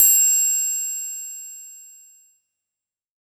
SOUTHSIDE_percussion_belliscious_F.wav